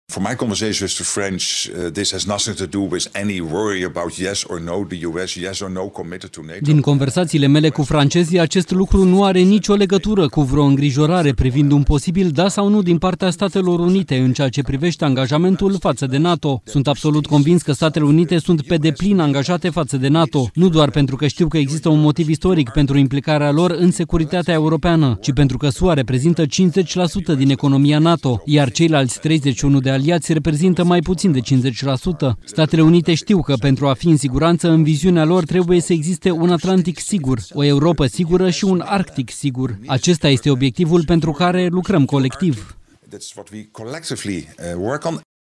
Extinderea umbrelei nucleare a Franței nu reprezintă un motiv de îngrijorare în ceea ce privește angajamentul Statelor Unite față de NATO, a declarat secretarul general al NATO, Mark Rutte, întrebat de jurnaliștii de la Reuters.
05mar-15-Rutte-Europa-sa-nu-si-faca-griji-TRADUS.mp3